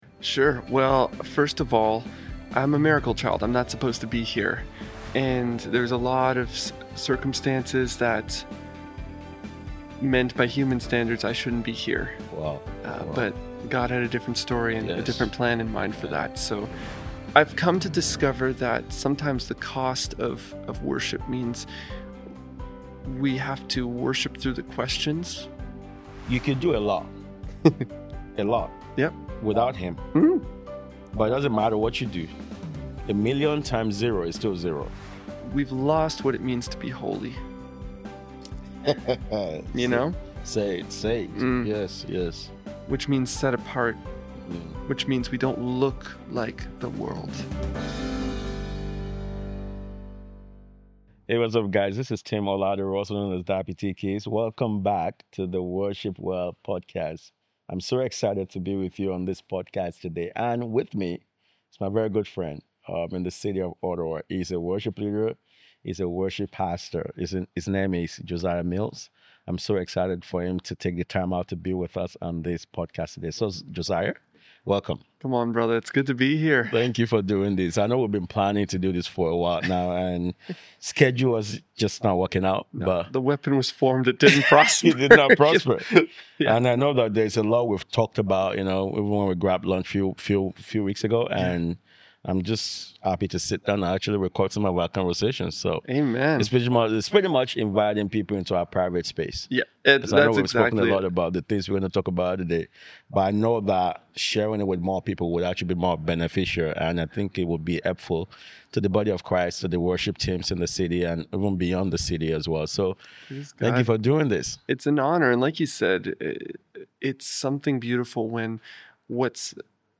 this conversation